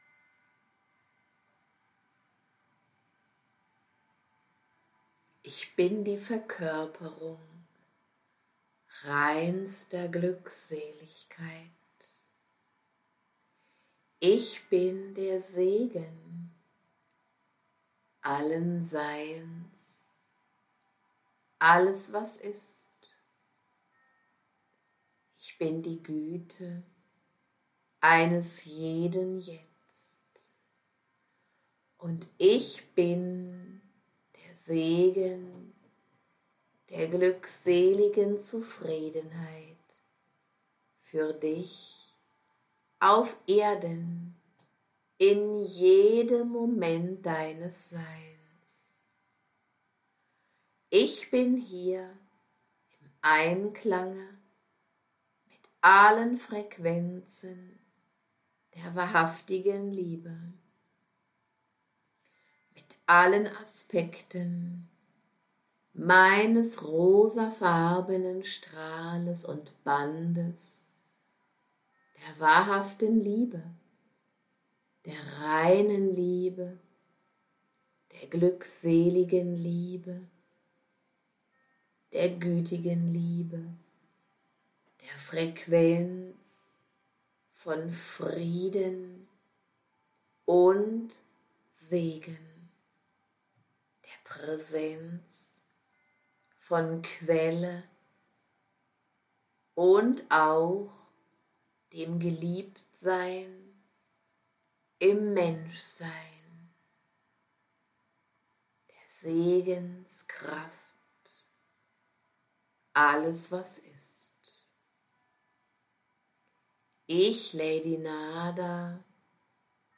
♥GRATIS Auszug des Abends (ca. 7 Minuten) voller reiner Liebe:
♡ Weitere Infos zum Live Channeling mit Impulsabend: